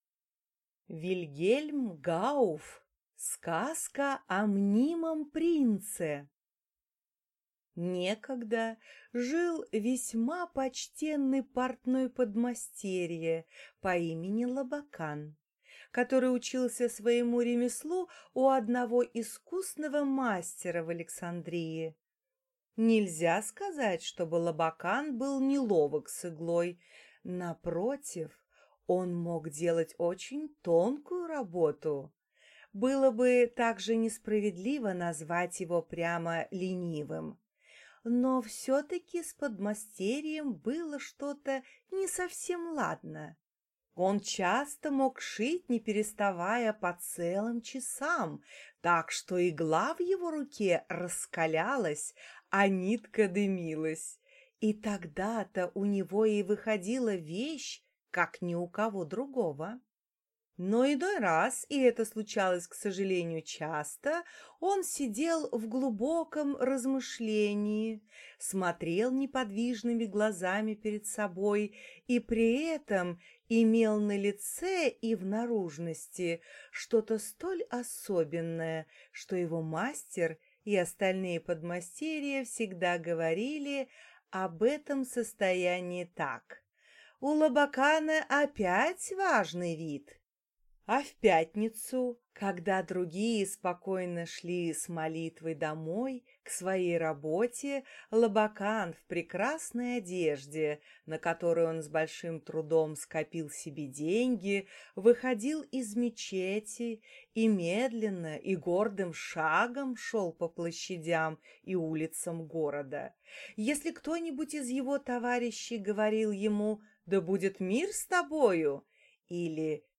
Аудиокнига Сказка о мнимом принце | Библиотека аудиокниг